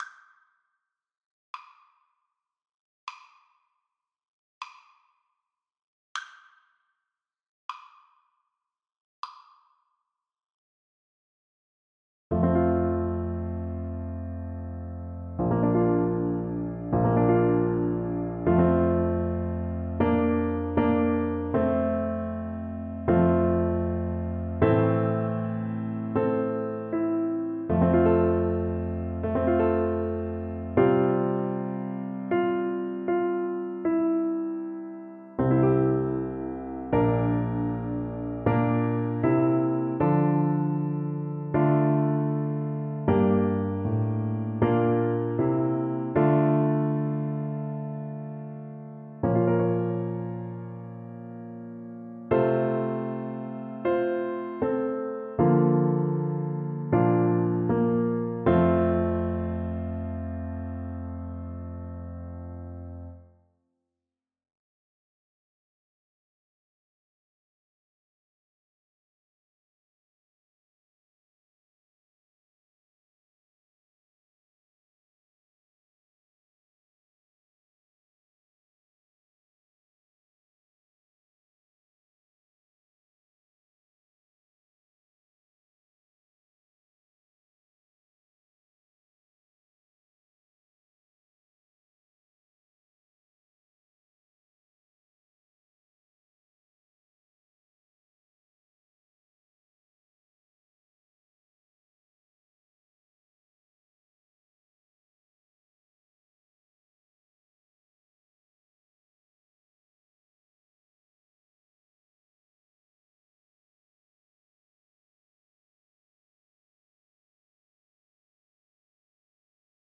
Slow =c.60
4/4 (View more 4/4 Music)